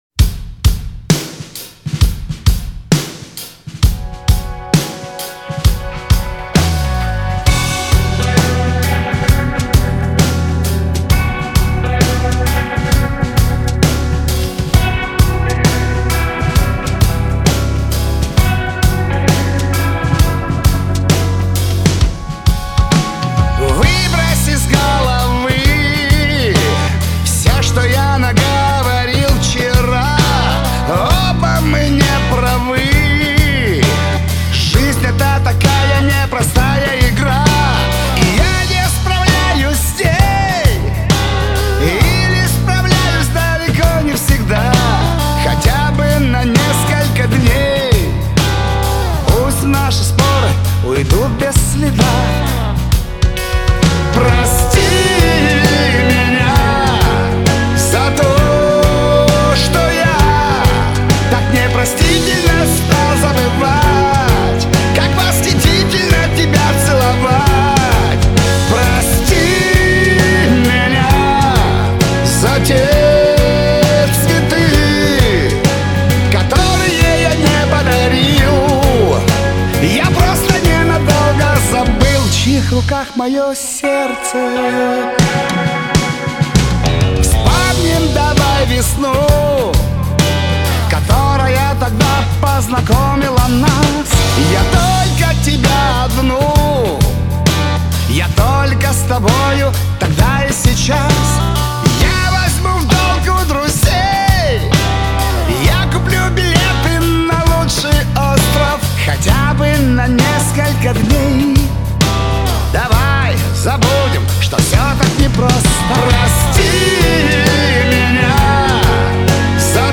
Трек размещён в разделе Русские песни / Шансон.